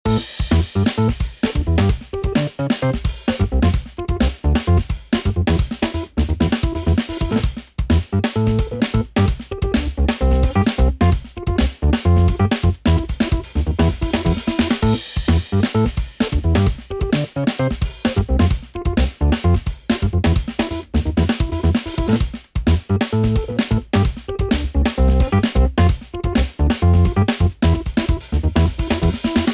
Some sound testing Monomono on EZD3 & RV8 :